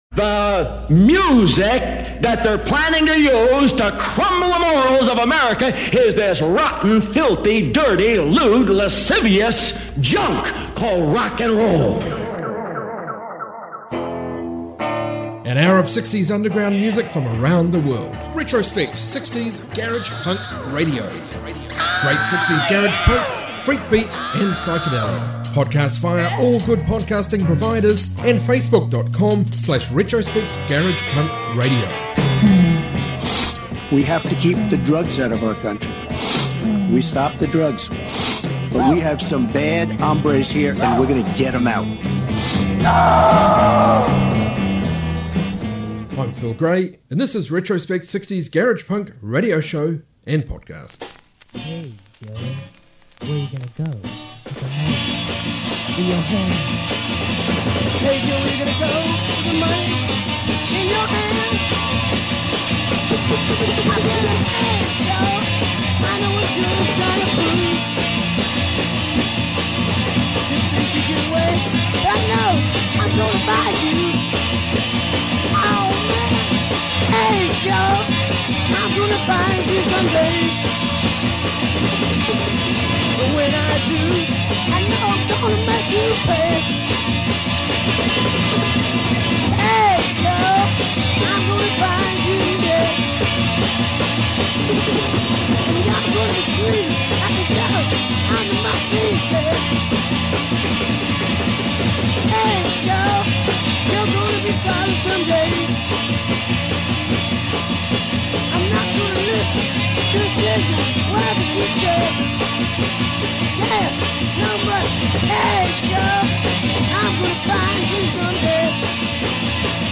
Global 60s garage rock garage punk & freakbeat